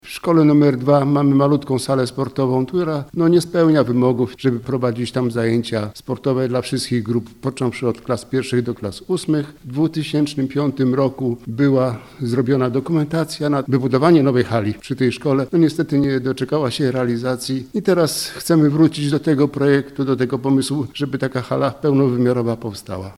– To pilna potrzeba, aby w Dwójce powstała nowa hala – mówi Zbigniew Biedulski, zastępca burmistrza Kostrzyna nad Odrą: